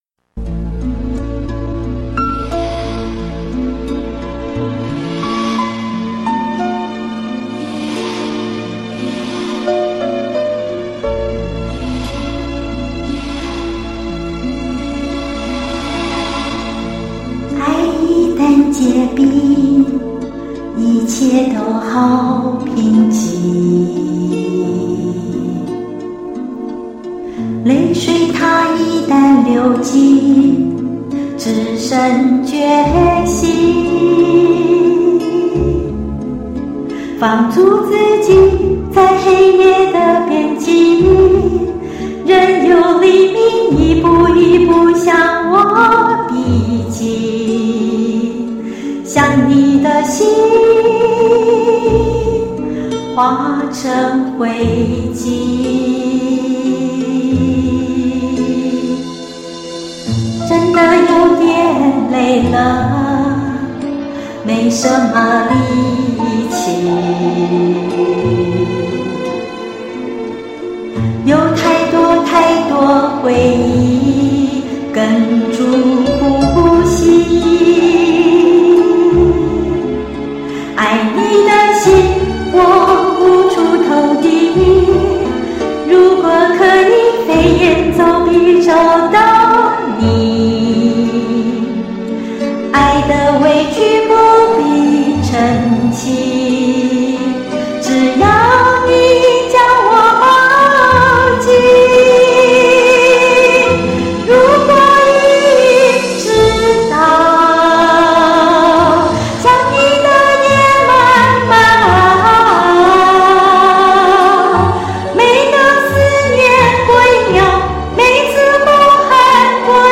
我可能要换一支麦克风了， 现在录唱时就算离麦克风远些也还是有杂音， 尤其唱到高音的时候。